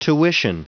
Prononciation audio / Fichier audio de TUITION en anglais
Prononciation du mot tuition en anglais (fichier audio)